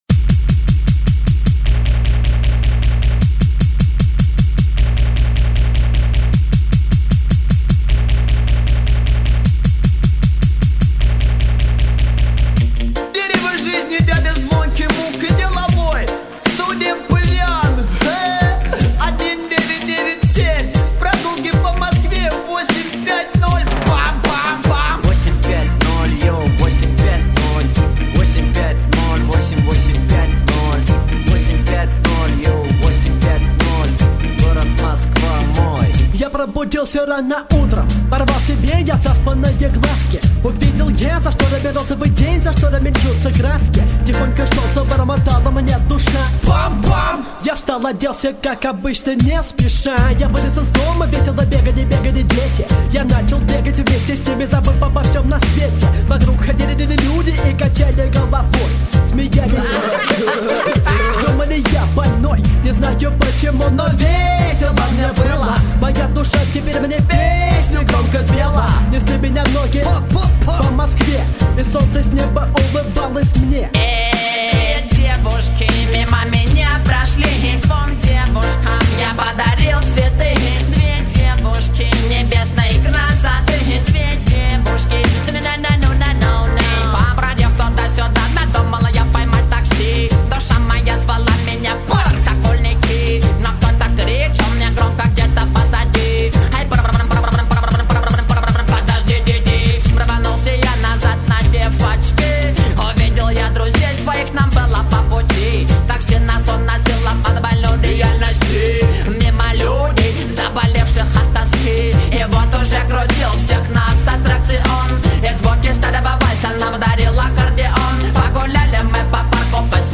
Это потрясающая музыка, приносящее лето и позитив!